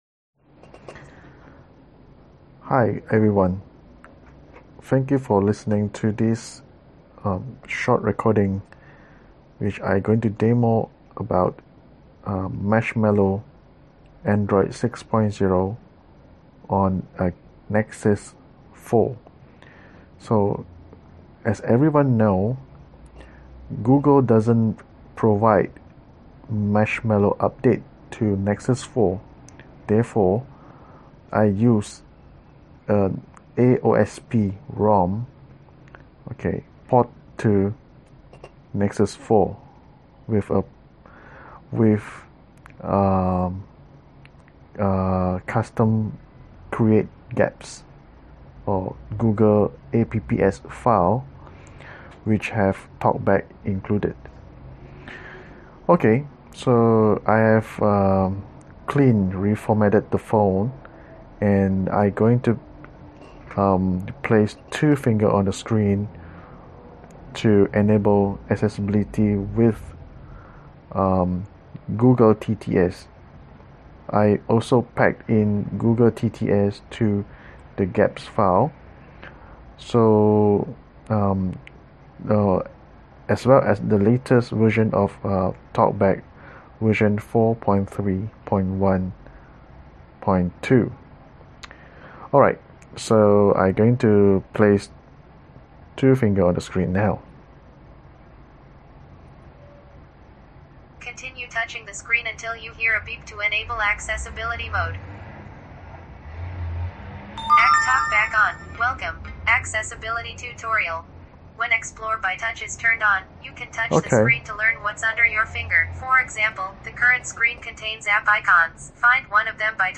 A Demonstration of Marshmallow_On_Nexus4.mp3